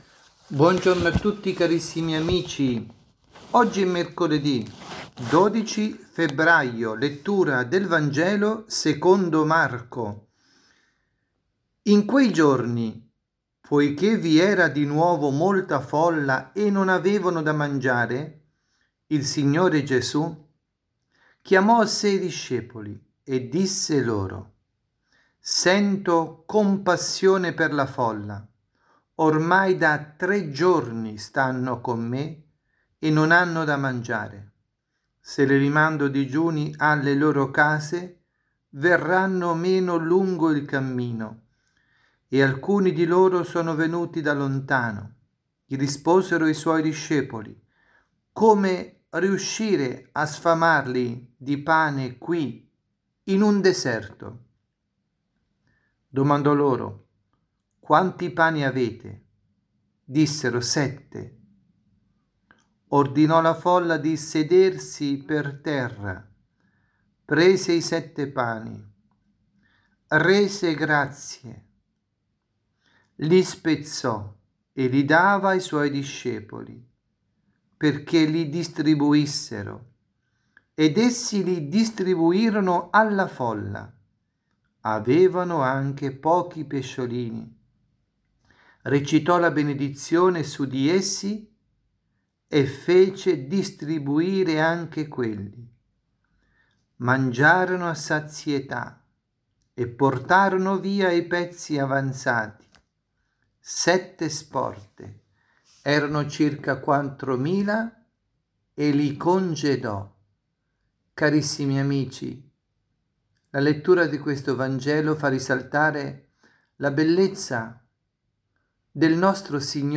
avvisi, Omelie
dalla Parrocchia S. Rita – Milano